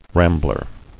[ram·bler]